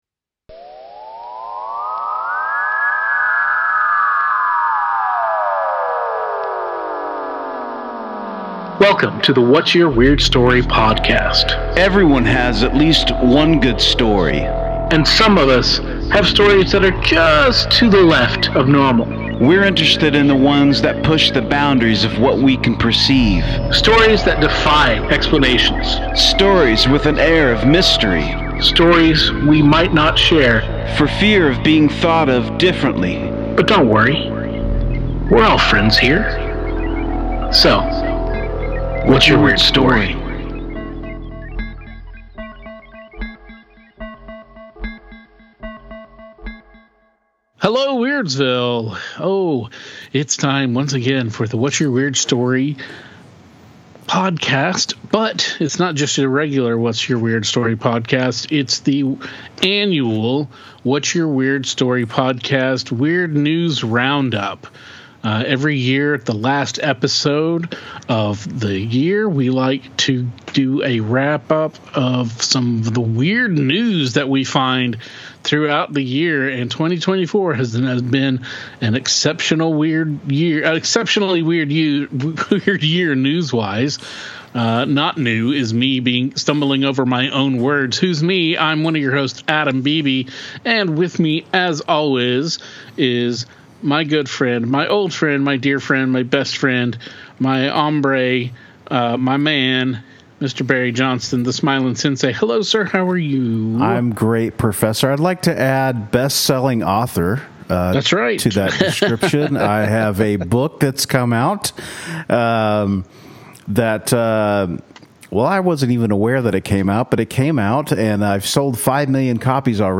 A fascinating and truly enlightening conversati…